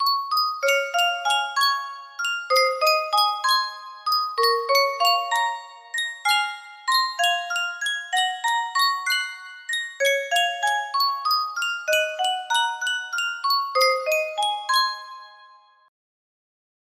Yunsheng Spilledåse - Det van en lørdag aften 5488 music box melody
Full range 60